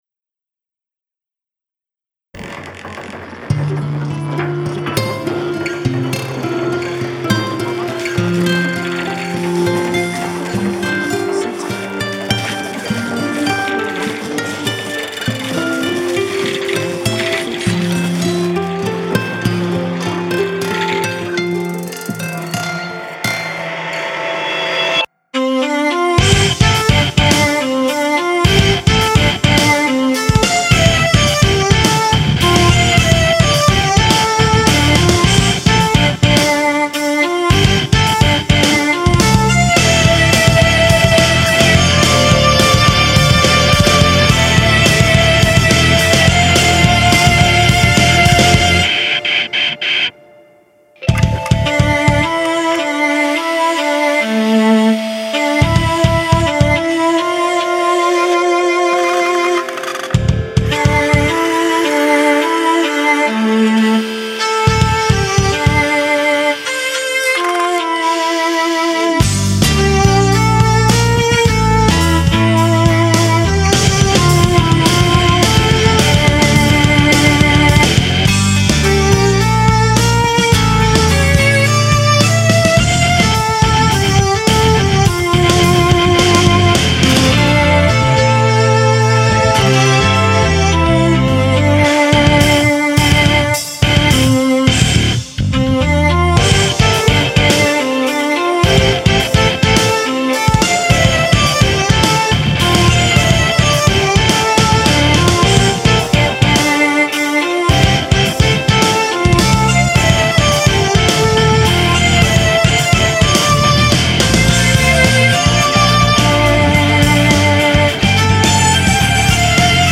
ボカロの歌はまだ入ってません。
2013年に制作したメロディックなラウド系ロック曲。